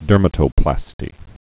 (dûrmə-tō-plāstē)